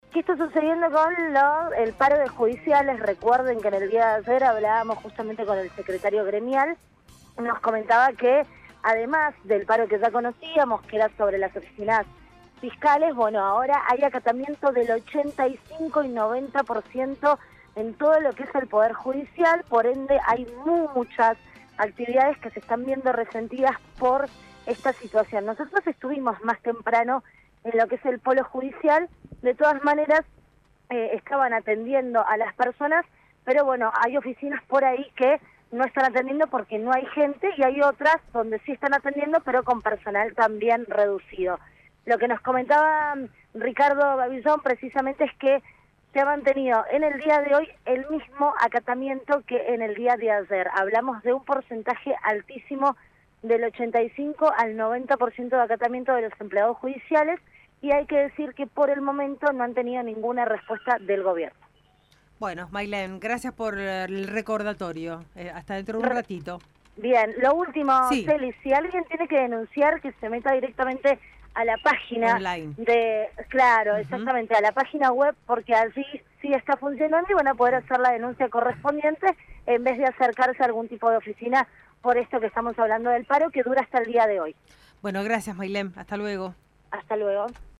Móvil de LVDiez- Paro de judiciales